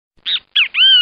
Bem Te Vi (bird Meme)